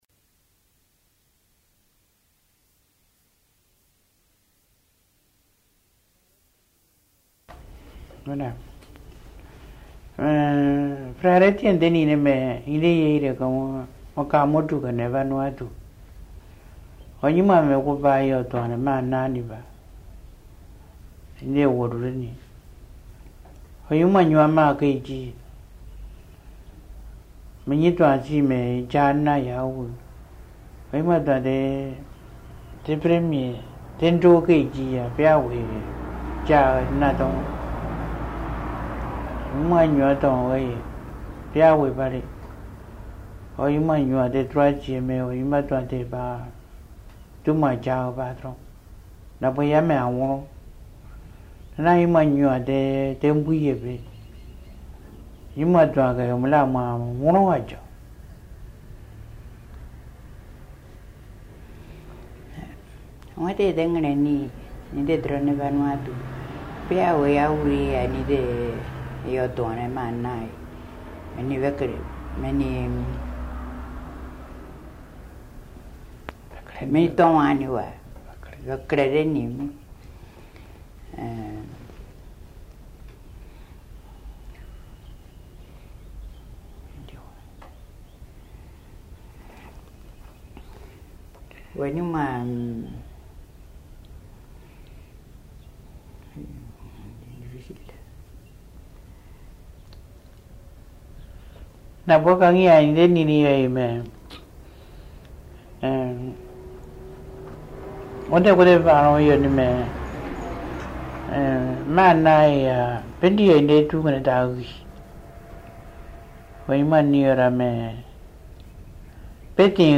Accueil > Dialogue > Dialogue > Drubéa